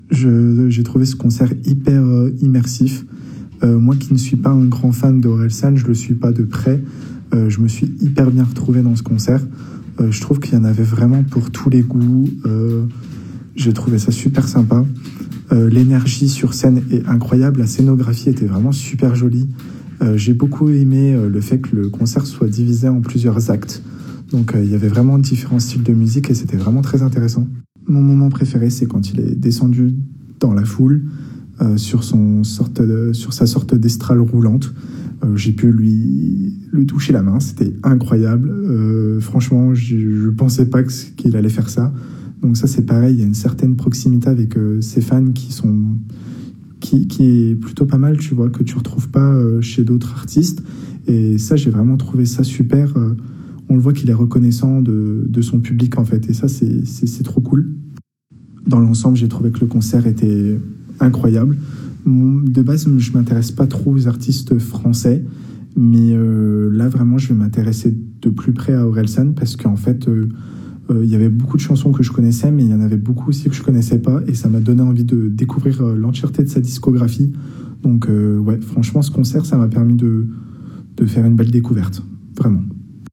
Il nous partage à chaud ses impressions sur l’ambiance et les moments forts qu’il a vécu pendant le concert :